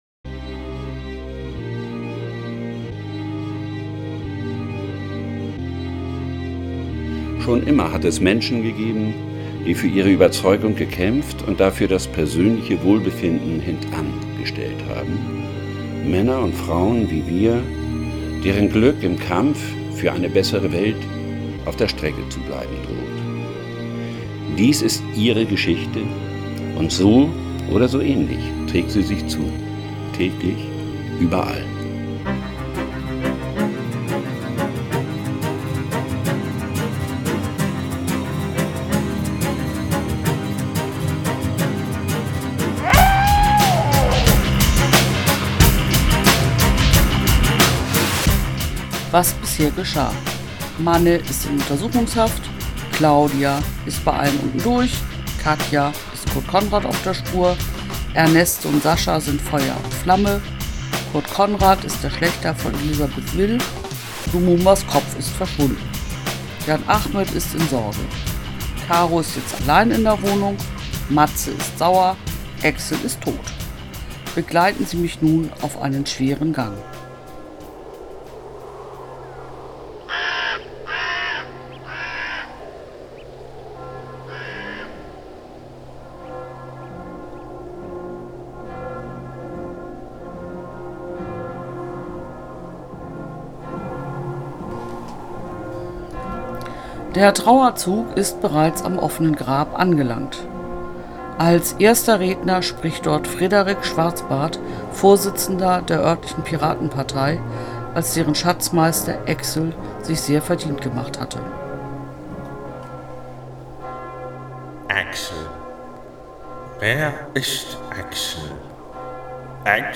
Überall – DIE Radionovela | 17grad